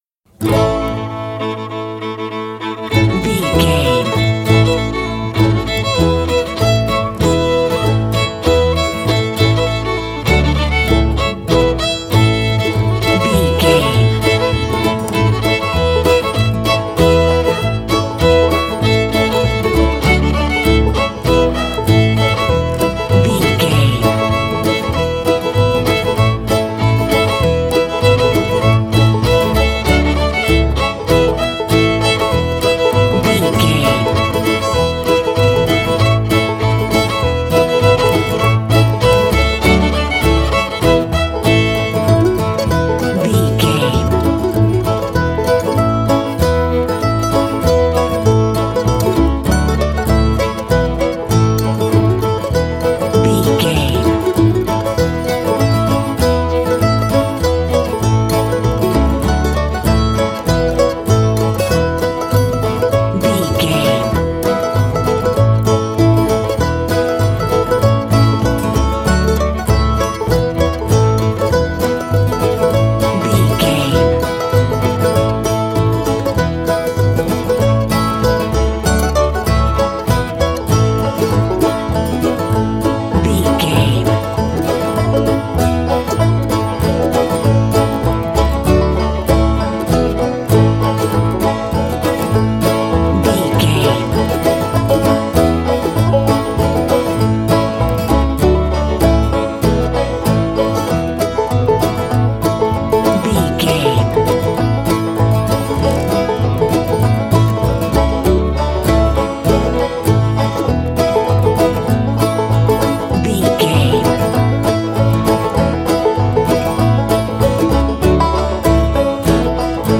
Ionian/Major
acoustic guitar
bass guitar
violin
banjo
bluegrass